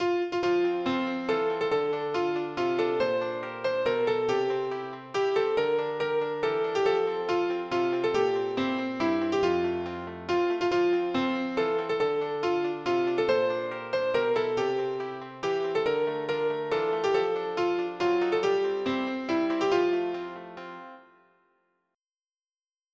MIDI Music File
Type General MIDI